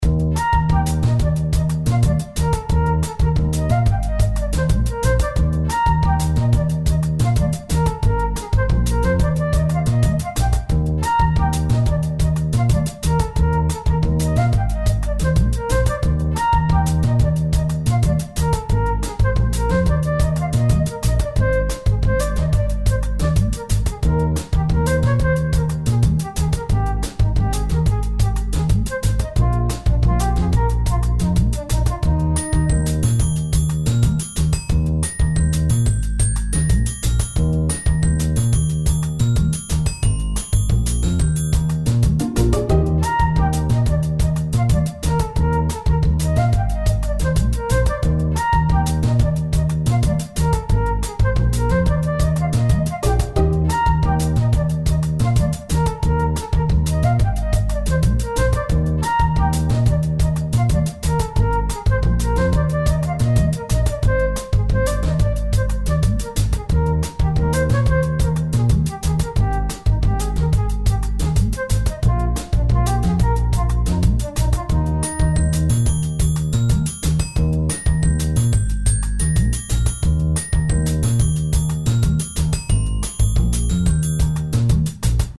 ファンタジー系フリーBGM｜ゲーム・動画・TRPGなどに！
おしゃれな町並みとかそういう感じのやつ。